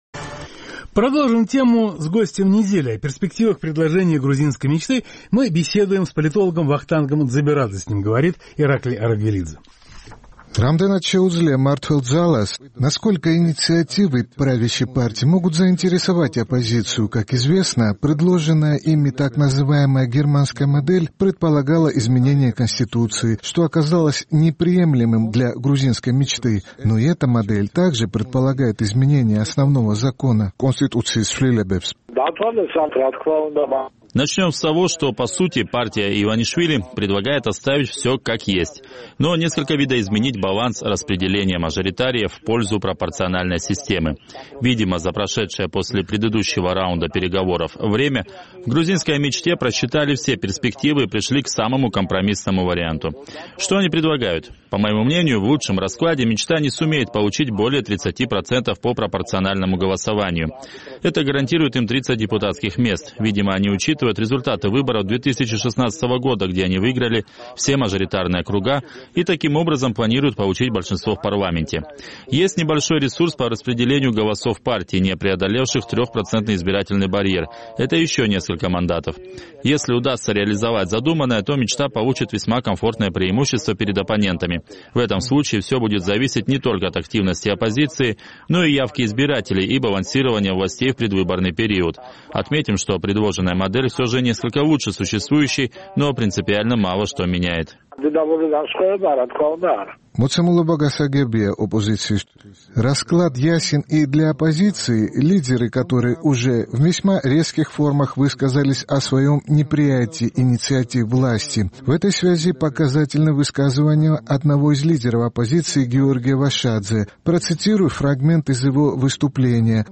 Гость недели